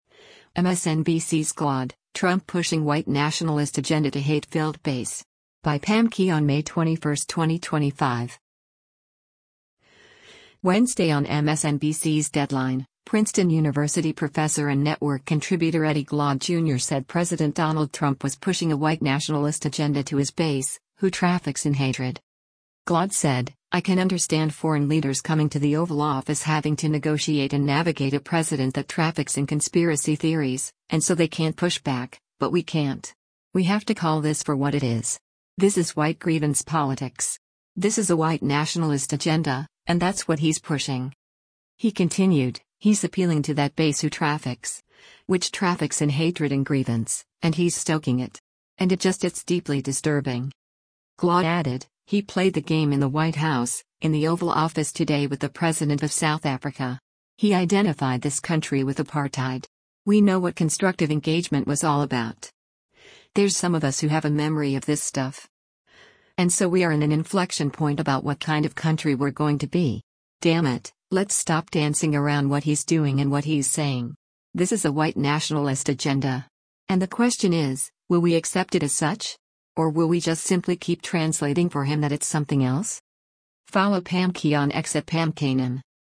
Wednesday on MSNBC’s “Deadline,” Princeton University professor and network contributor Eddie Glaude, Jr. said President Donald Trump was pushing a “white nationalist agenda” to his base, who “traffics in hatred.”